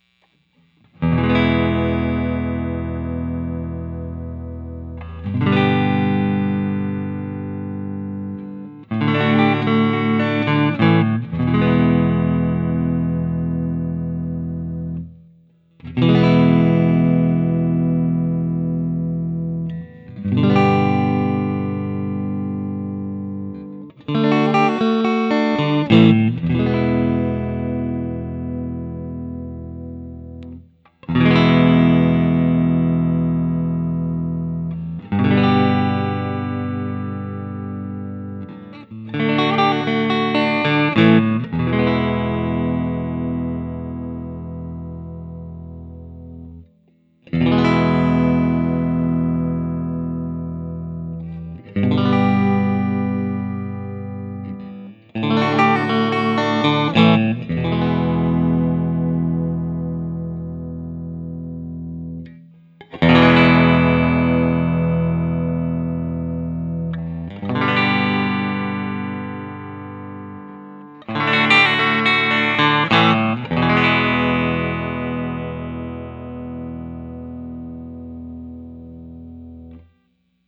Plugged in it sounds like a Strat.
’59 Bassman
Open Chords #1
I recorded the guitar into my normal Axe-FX III/Audacity setup, but for this guitar I’m not doing a full review so I just used a ’59 Bassman recreation because those amps always sound great with a Strat to me. For the first five recordings I had the compressor on and I think it made everything sound a bit flat and harsh because as soon as I turned it off I liked it better, but I’m too lazy to re-record.
For most of the recordings I play my usual test chords through all five of the pickup settings in the following order: